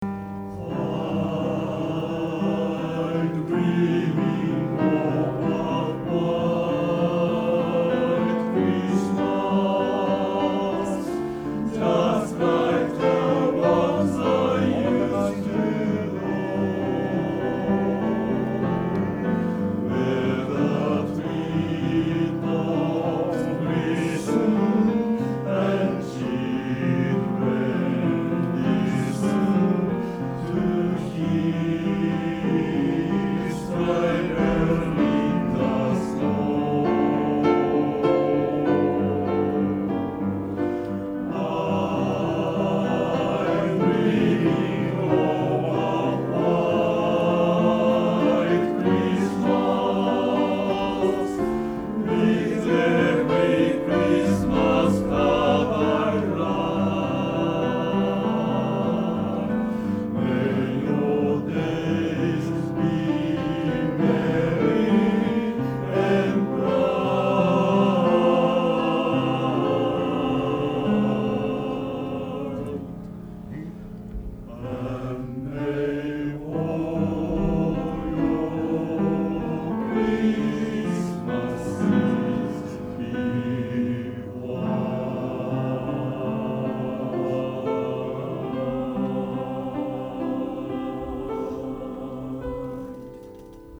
練習場所：明石市立勤労福祉会館　2階第5会議室（明石市）
出席者：37名（sop12、alt11、ten8、bass6）
→クリスマスメドレー4曲通し